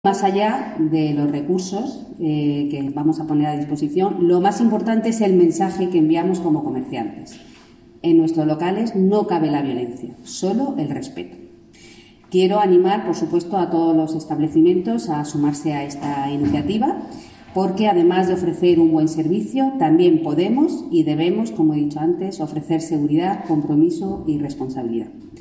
Declaraciones de la concejala de Comercio, Beatriz Cano